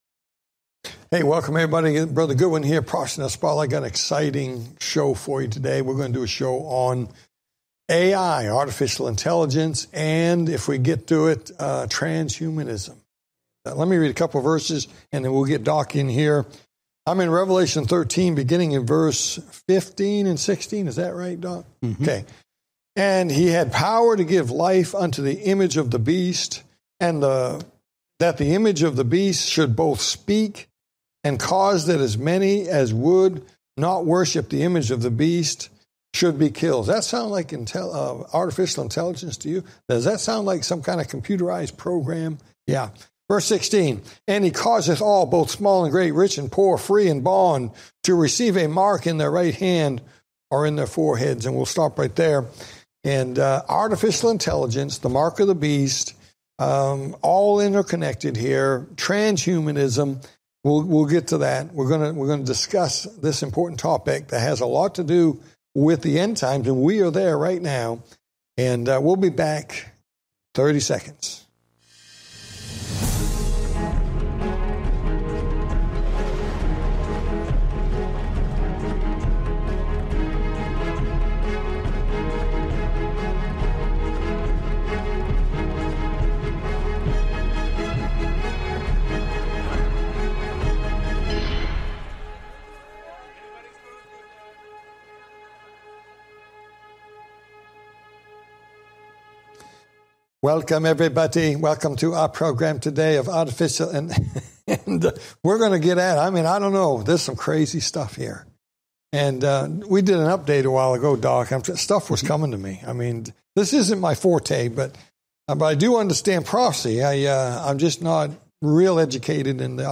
Talk Show Episode, Audio Podcast, Prophecy In The Spotlight and AI And Transhumanism, and Redemption During The Tribulation on , show guests , about AI And Transhumanism,Redemption During The Tribulation, categorized as History,News,Politics & Government,Religion,Society and Culture,Theory & Conspiracy